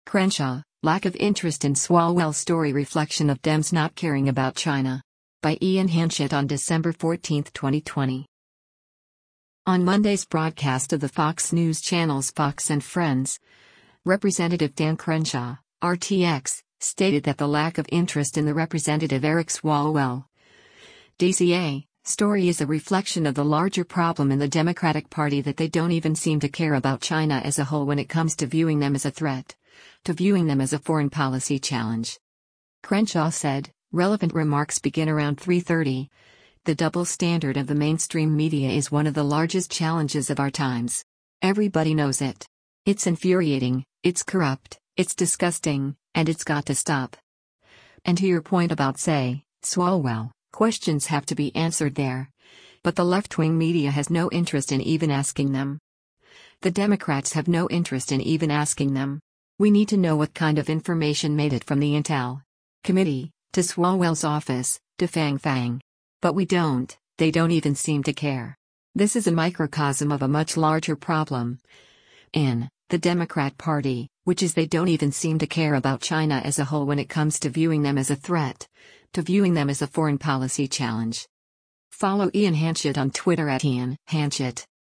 On Monday’s broadcast of the Fox News Channel’s “Fox & Friends,” Rep. Dan Crenshaw (R-TX) stated that the lack of interest in the Rep. Eric Swalwell (D-CA) story is a reflection of the larger problem in the Democratic Party that “they don’t even seem to care about China as a whole when it comes to viewing them as a threat, to viewing them as a foreign policy challenge.”